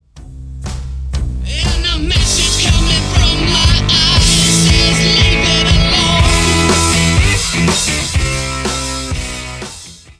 Музыка » Rock » Rock